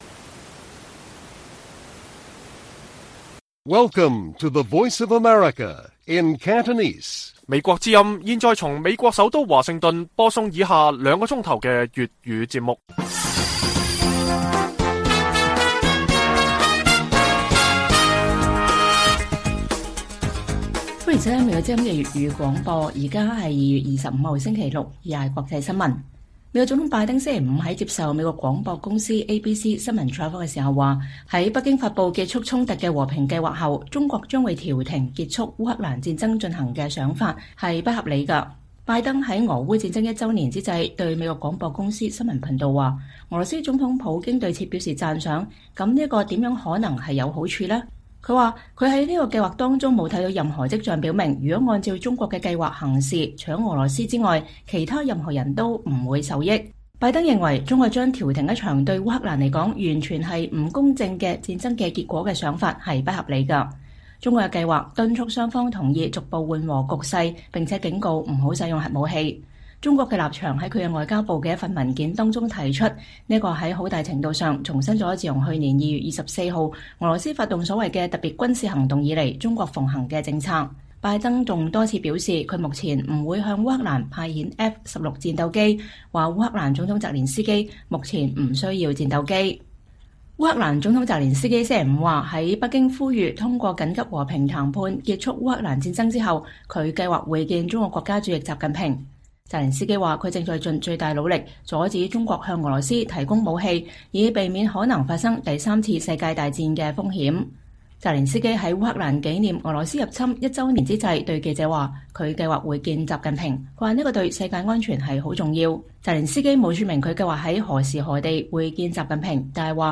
粵語新聞 晚上9-10點: 拜登：由中國調停俄烏戰爭“不合理”